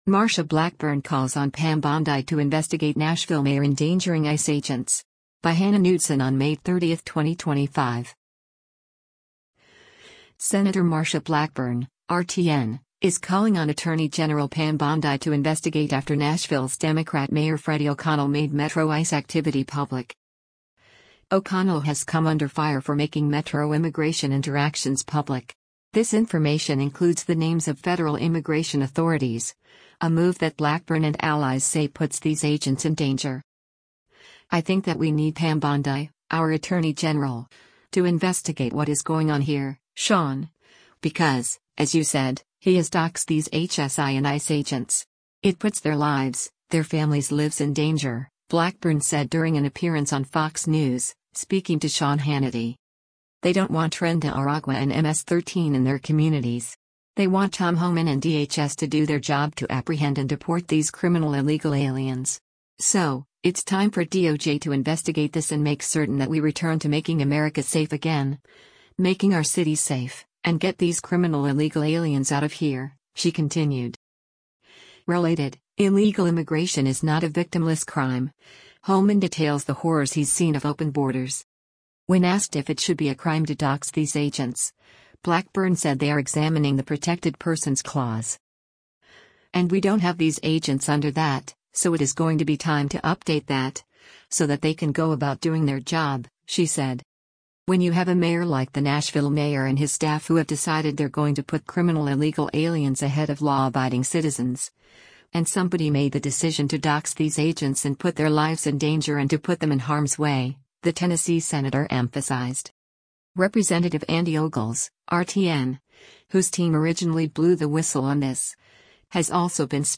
“I think that we need Pam Bondi, our attorney general, to investigate what is going on here, Sean, because, as you said, he has doxed these HSI and ICE agents. It puts their lives, their families’ lives in danger,” Blackburn said during an appearance on Fox News, speaking to Sean Hannity.